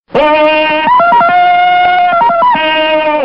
category : Sound Effects